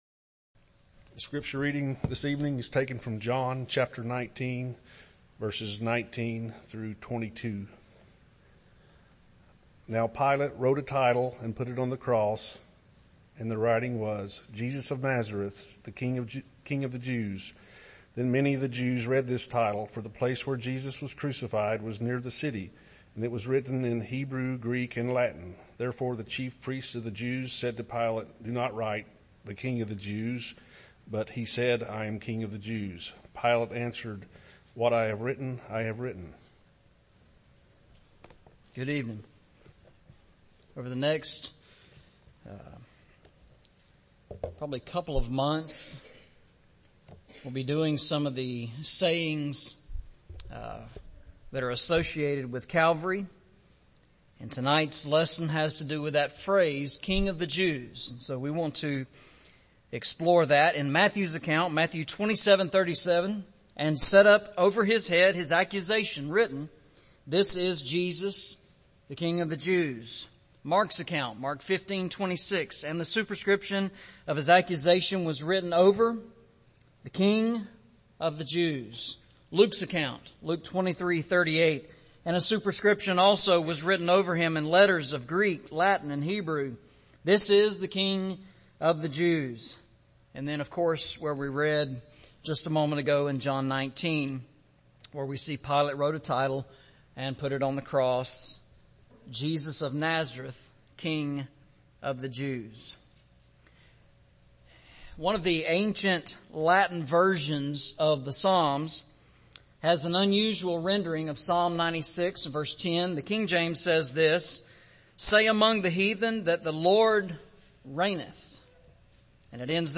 Series: Eastside Sermons Service Type: Sunday Evening Preacher